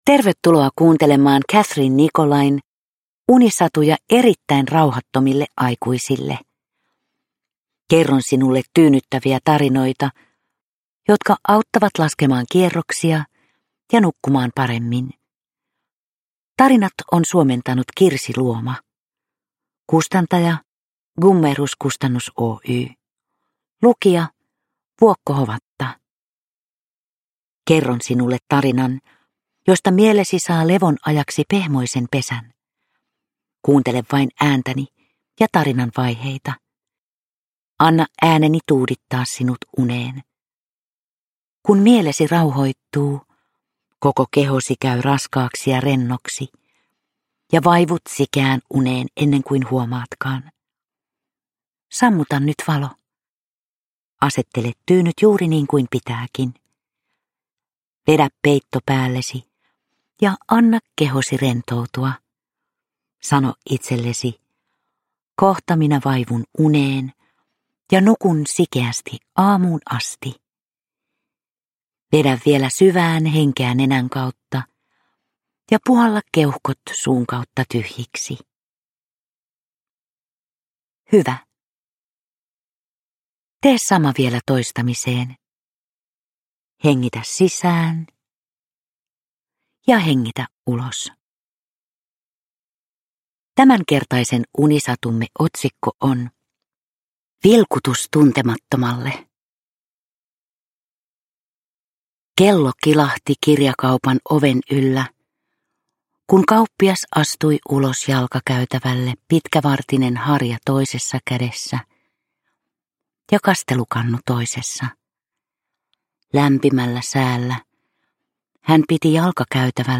Unisatuja erittäin rauhattomille aikuisille 2 - Vilkutus tuntemattomalle – Ljudbok